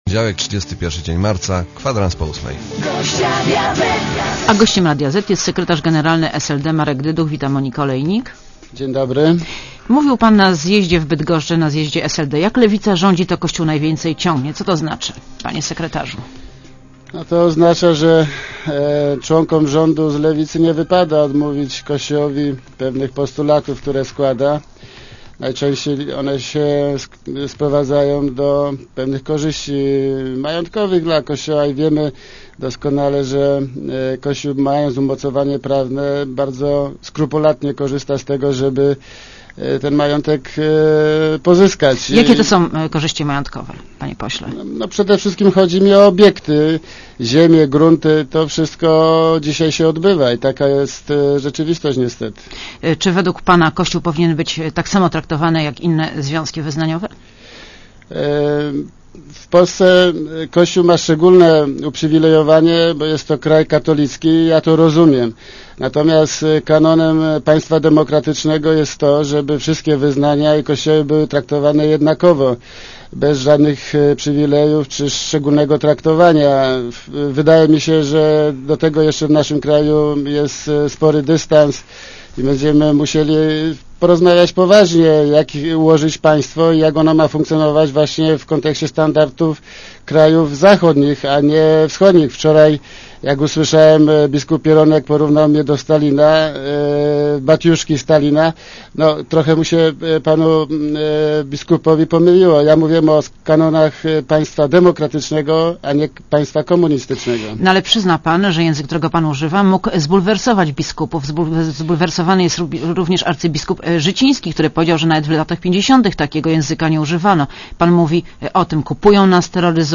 Monika Olejnik rozmawia z Markiem Dyduchem - sekretarzem generalnym SLD